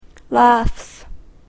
/læfs(米国英語)/